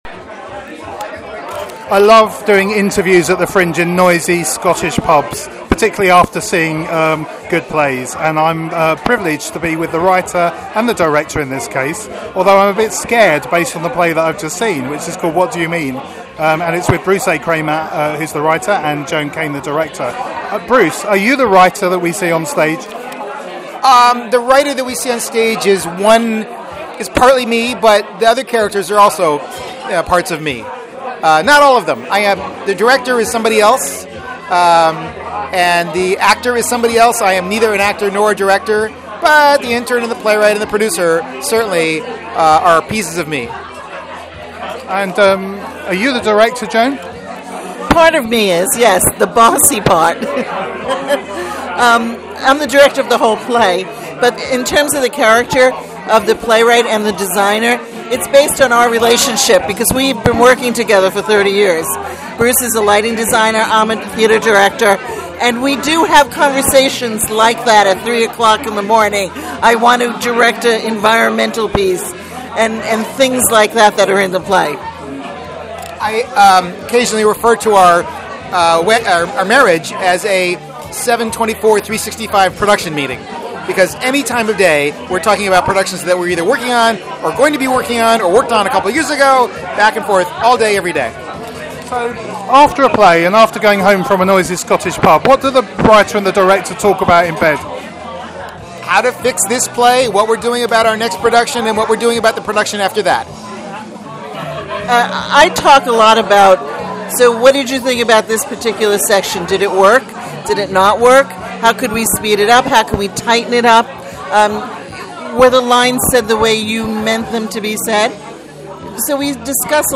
Edinburgh Audio 2014